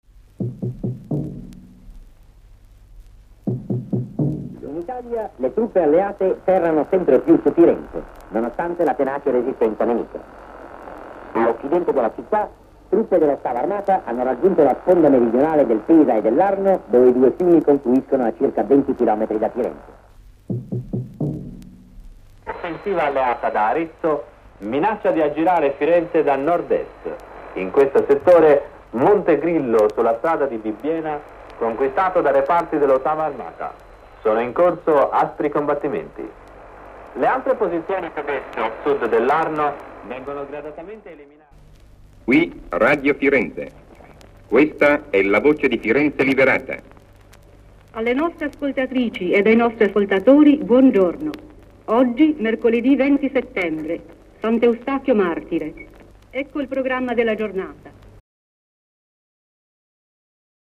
Documento sonoro di Radio Londra
Radio Londra annuncia che le avanguardie alleate si stanno avvicinando a Firenze, 31 luglio 1944; Radio Londra annuncia che ormai la liberazione di Firenze è imminente; trasmissione di Radio Firenze liberata, 20 settembre 1944